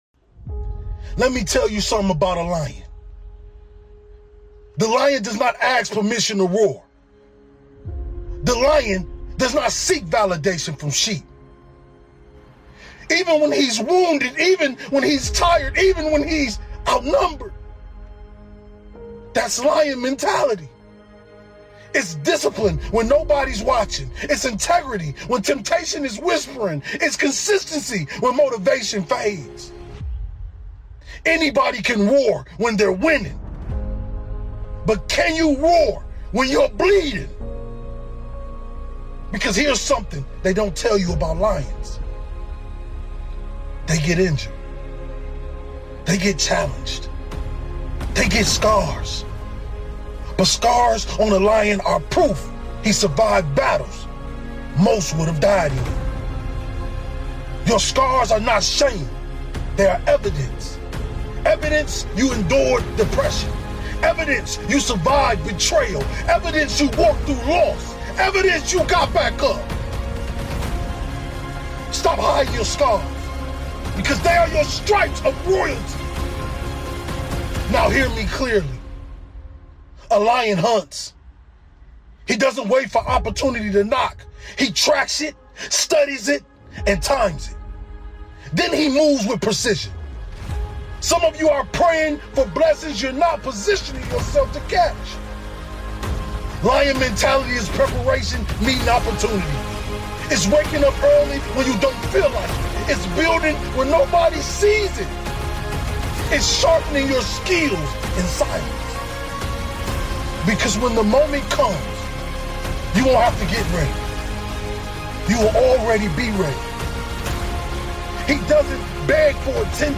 This is a episode on the mentality it takes to achieve greatness. Powerful Motivational Speech